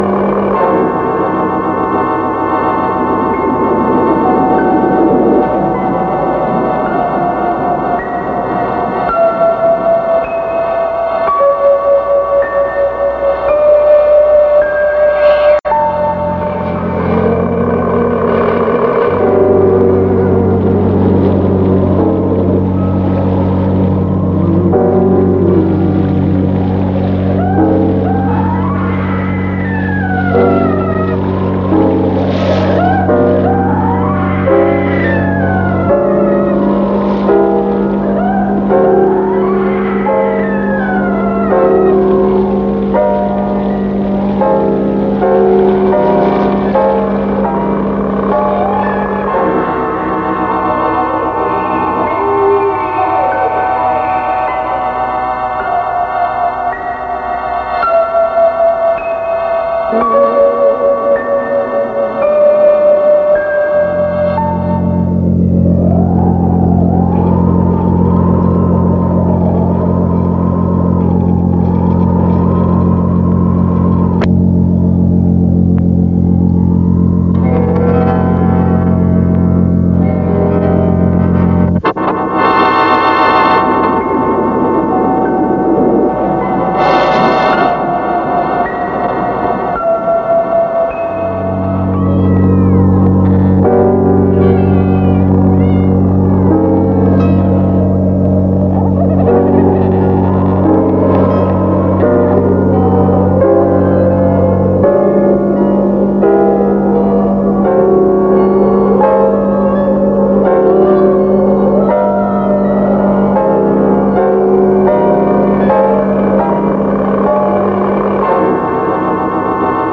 New Spooks Sound Track.
Then a modified Midway Haunted House sound track plays during the Bullseye Flash scene.
background sound track (0001.mp3).